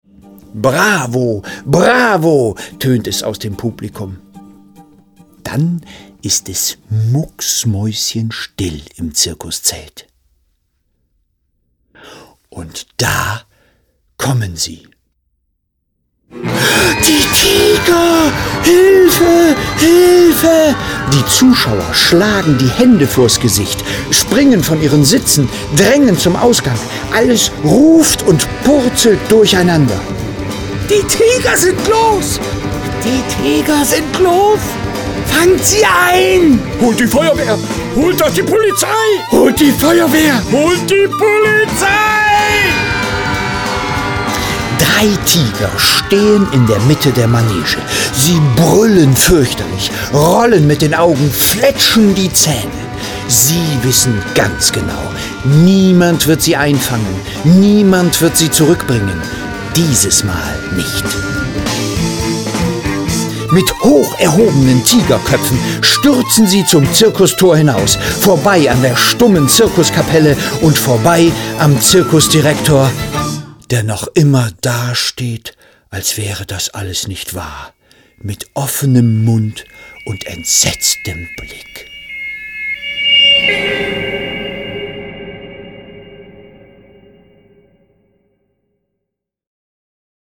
Hörstück zu einem Papiertheaterstück von Heike Ellermann.
mit der Musik des TRILLKE TRIO sowie der Gruppe LANDMUSIGG.